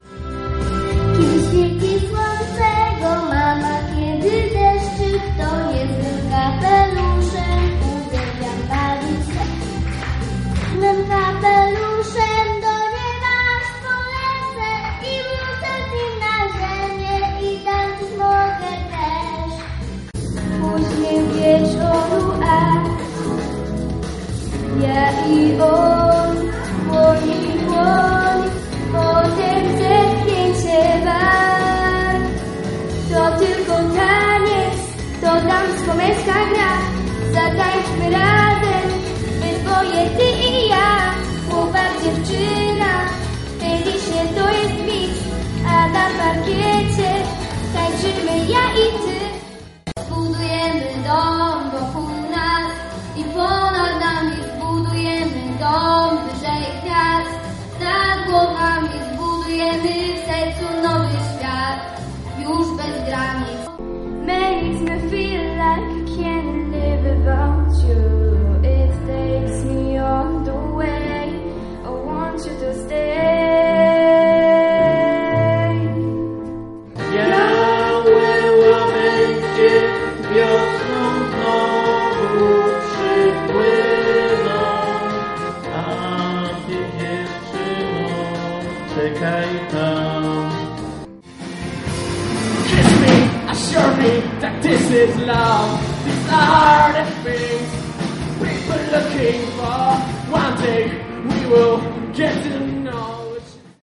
14 listopada w Żnińskim Domu Kultury odbył się finał II Powiatowego Festiwalu Talentów.
1-spiew-na-strone.mp3